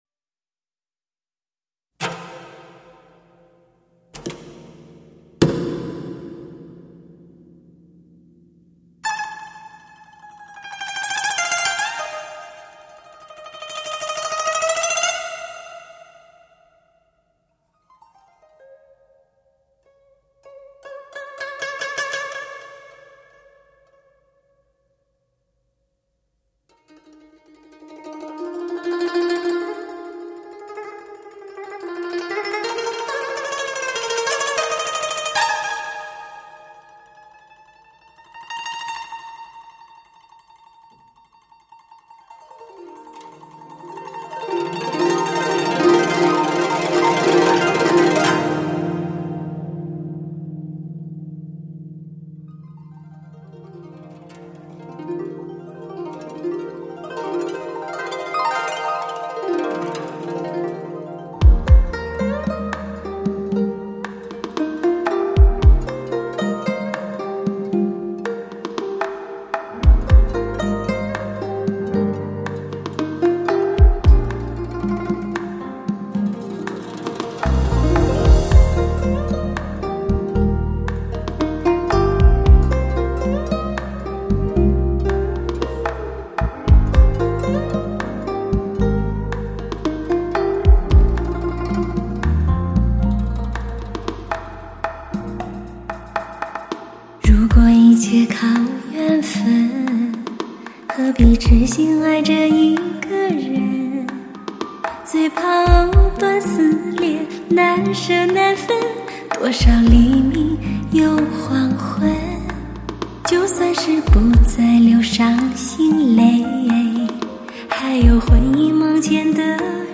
类型: 汽车音乐
唱片介绍令人欲罢不能的音效，极具穿透力，将你带入超乎完美的发烧音乐境界……